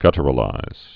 (gŭtər-ə-līz)